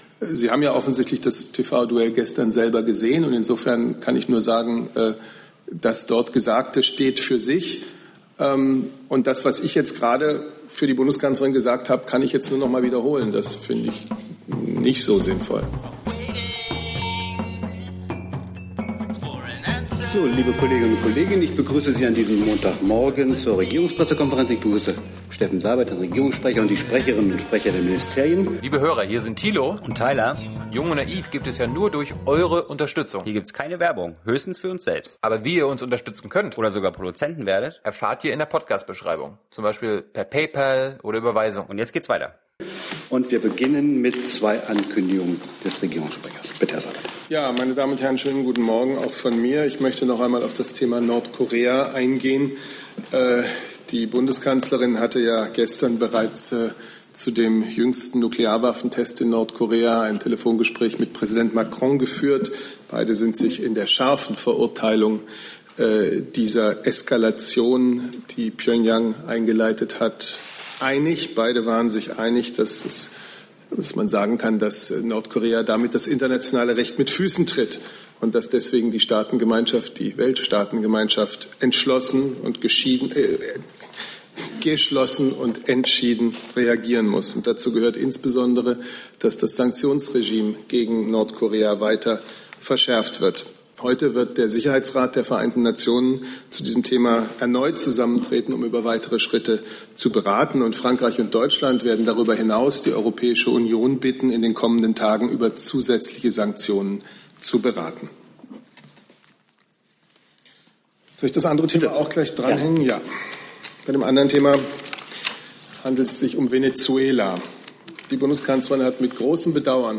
RegPK - 04.09.2017 - Regierungspressekonferenz ~ Neues aus der Bundespressekonferenz Podcast